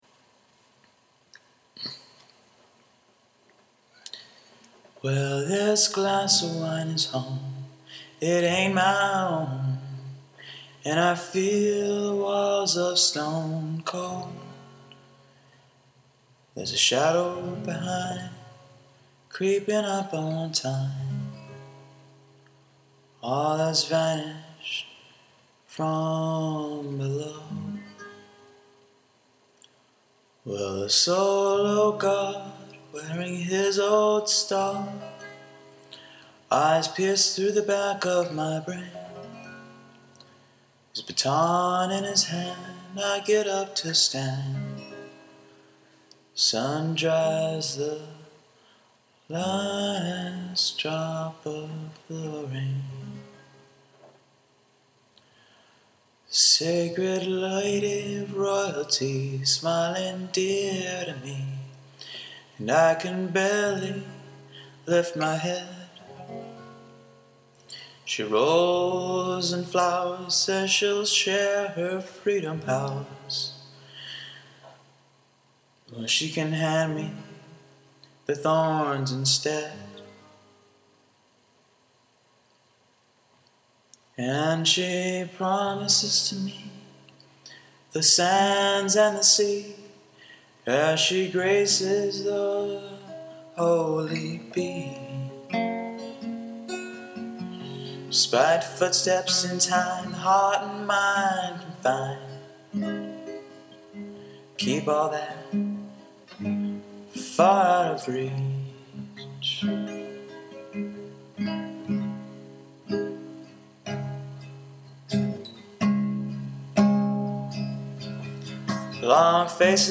Demo Recording